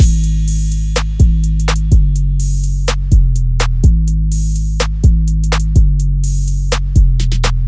德雷克式陷阱鼓
描述：楼梯鼓的循环。
标签： 125 bpm Trap Loops Drum Loops 1.29 MB wav Key : G
声道立体声